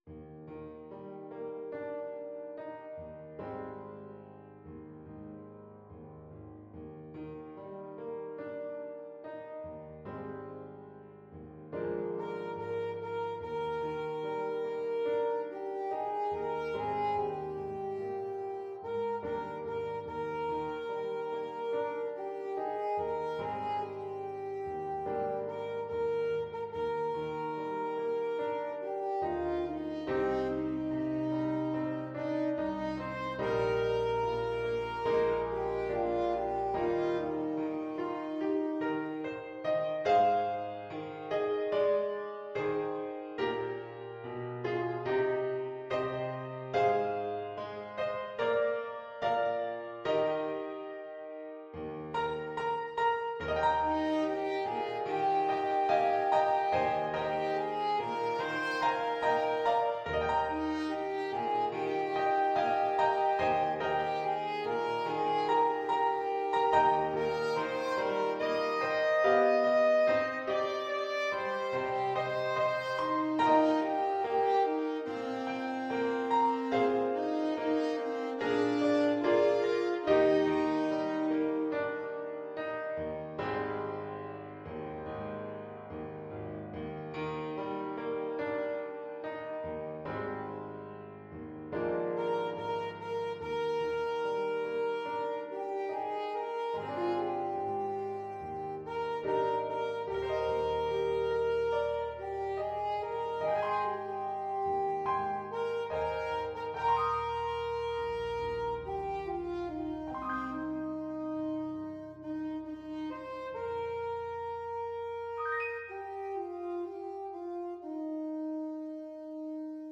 Alto Saxophone
Traditional Music of unknown author.
4/4 (View more 4/4 Music)
Molto espressivo =c.72